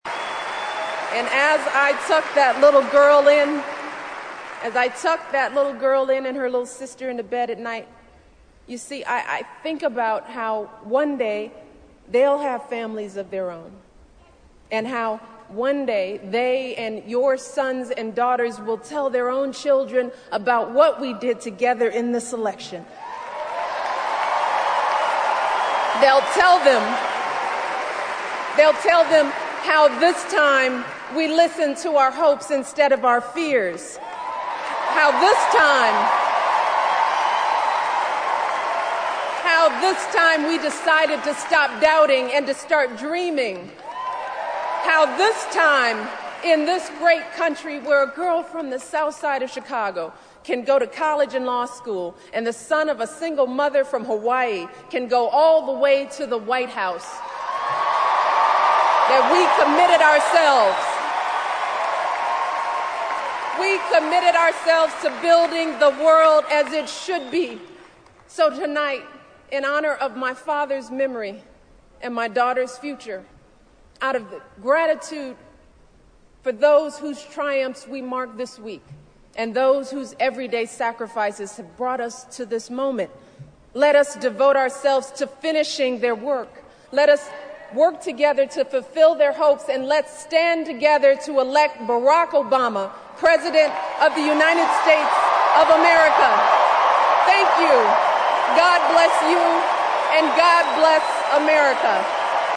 名人励志英语演讲 第97期:让我们选举巴拉克·奥巴马为美利坚合众国总统(10) 听力文件下载—在线英语听力室